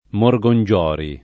[ mor g on J0 ri ]